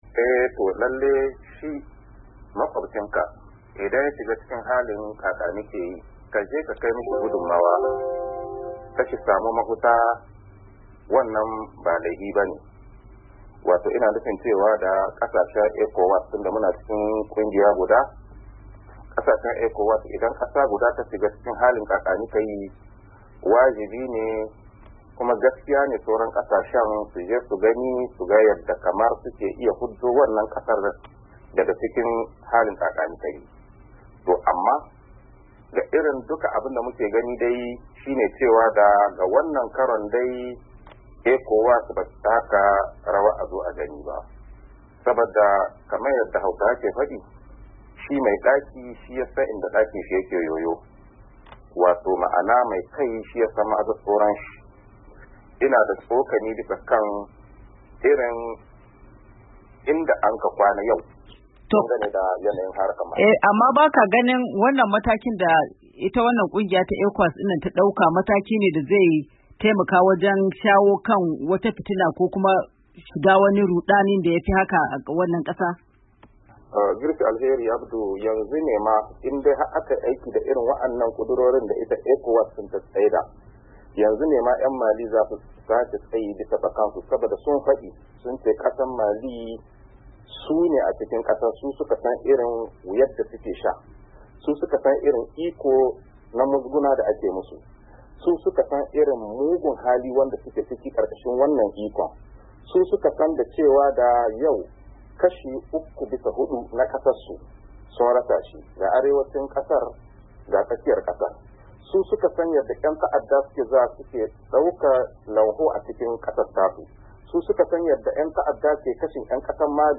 Ga dai tattaunawar